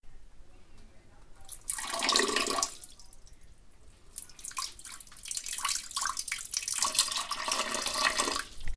Persona miccionando
Grabación sonora del sonido producido por el chorro de una persona miccionando, o haciendo pis, en un bater
Sonidos: Acciones humanas